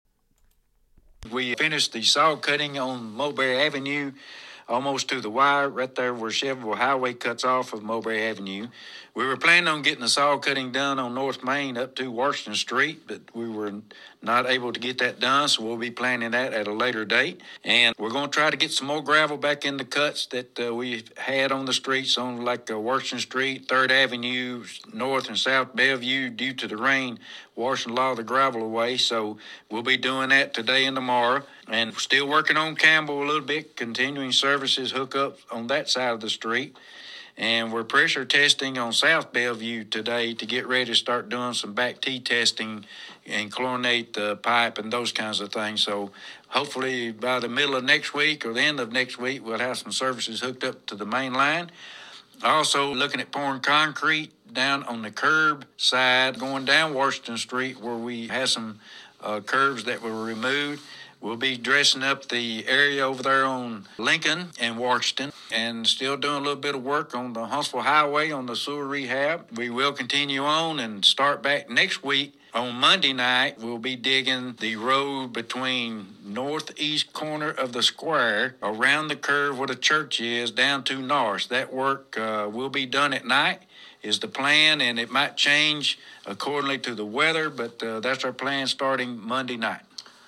(soundbite found in link above)